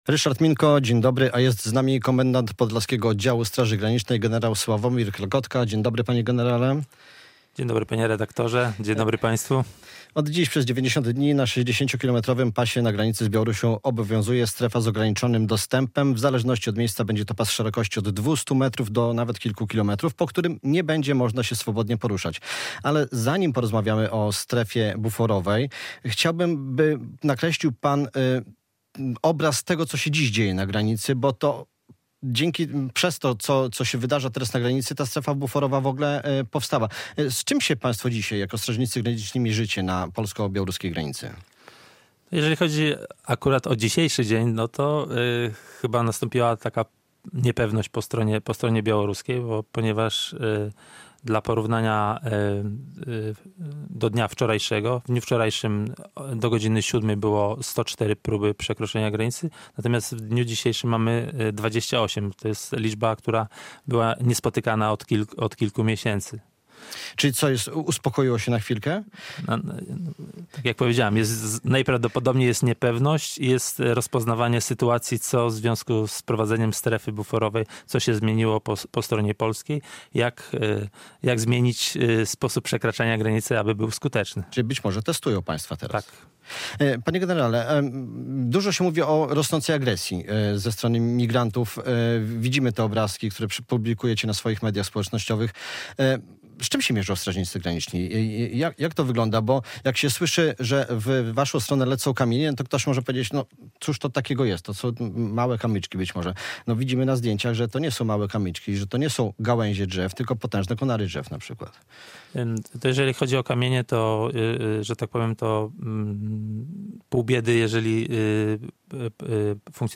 Radio Białystok | Gość | gen. Sławomir Klekotka [wideo] - komendant Podlaskiego Oddziału Straży Granicznej